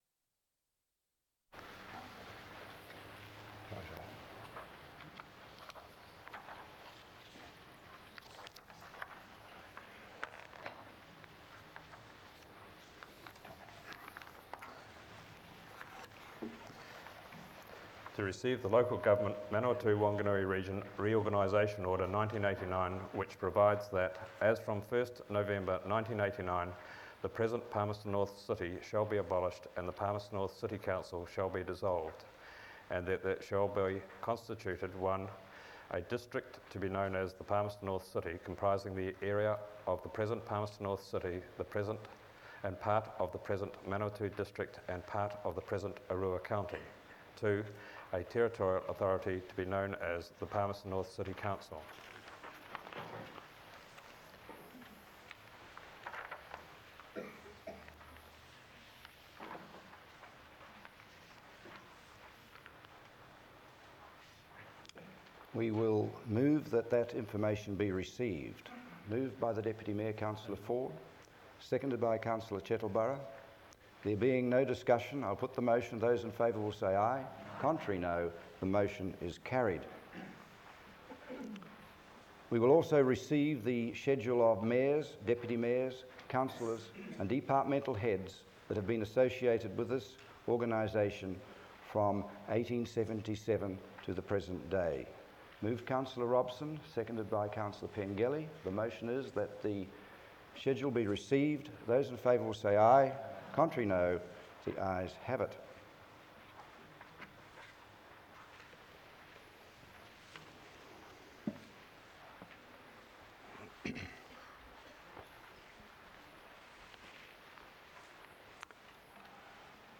Sound Recording - Last meeting of the Palmerston North City Council before reorganisation
This meeting is the last of the former Palmerston North City Council on 1 November 1989. As from this date the new Palmerston North City Council was formed, comprising of the then present Palmerston North City and parts of the then present Manawatu District and Oroua District Councils.